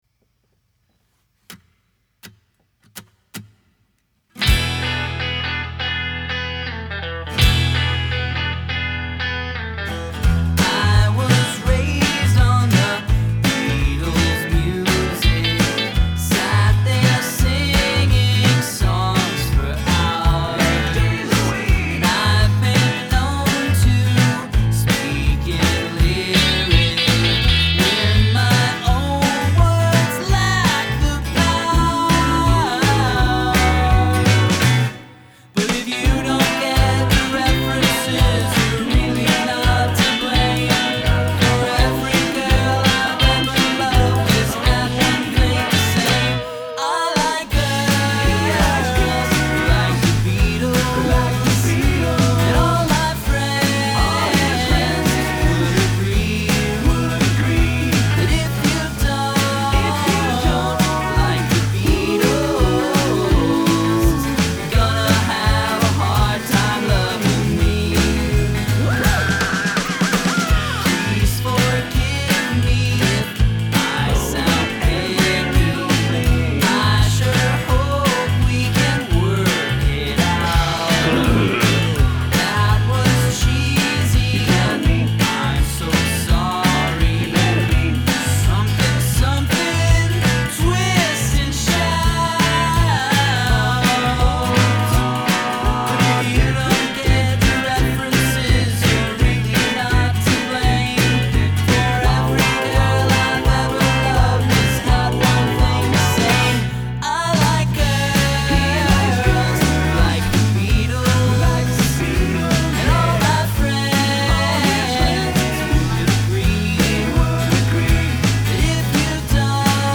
also has the 1960s sound down